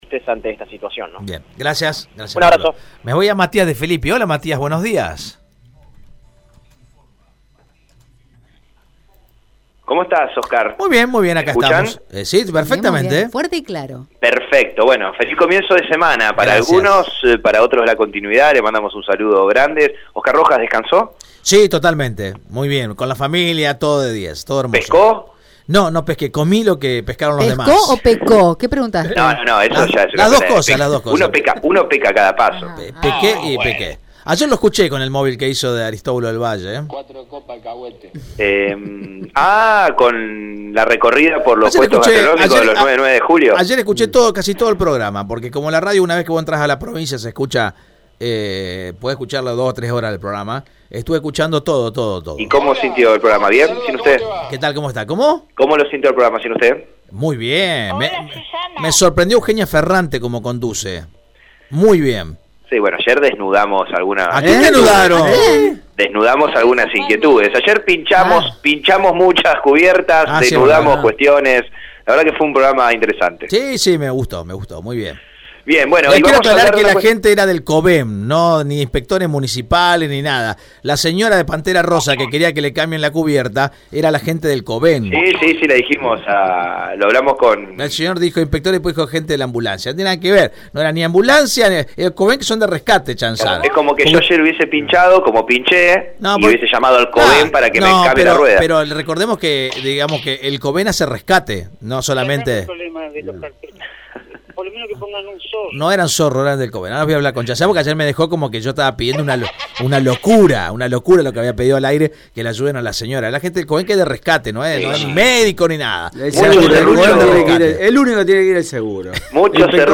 Marcelo Ponce, concejal del FPCyS, dijo a Radio EME que el colectivos «es el transporte de la clase media trabajadora y de los estudiantes».
Marcelo Ponce en el móvil de RADIO EME: